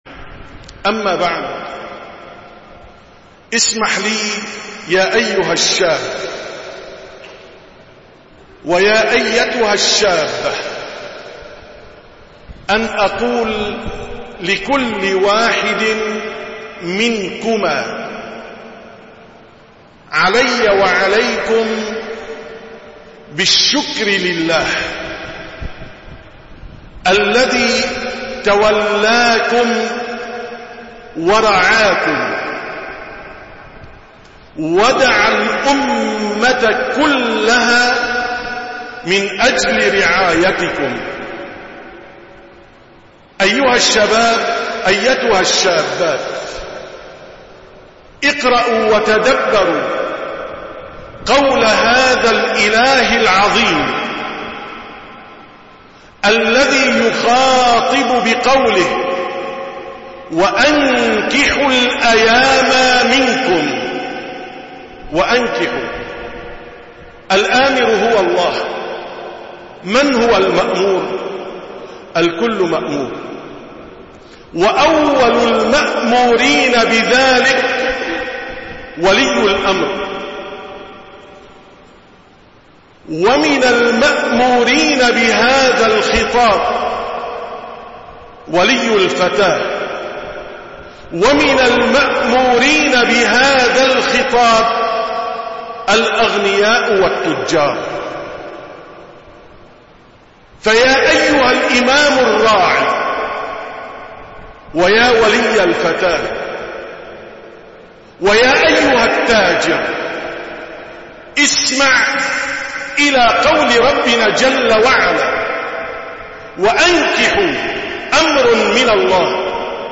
27- كلمة مختصرة في صلاة التراويح 1440هــ: يا شباب وشابات الأمة
دروس رمضانية